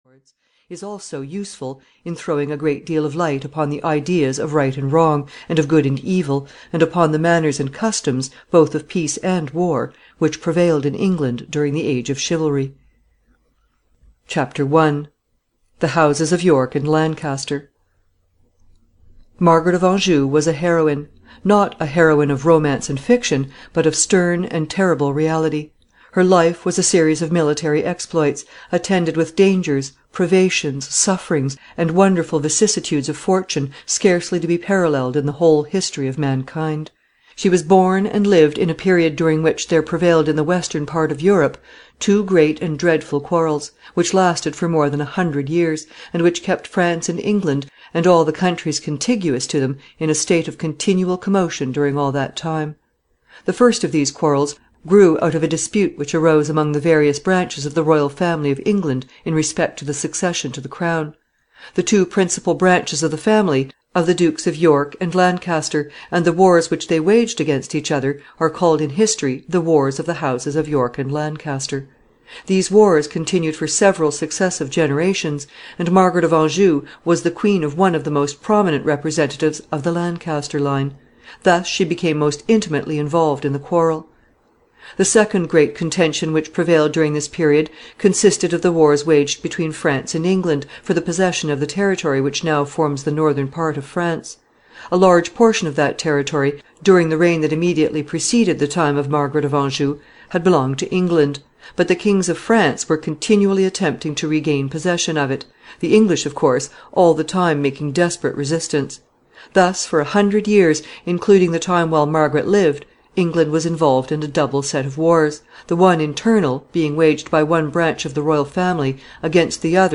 Margaret of Anjou (EN) audiokniha
Ukázka z knihy